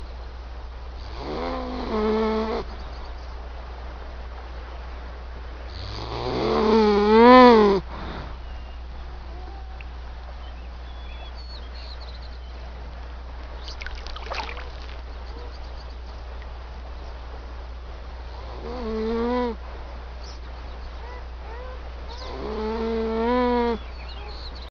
bear-sounds.mp3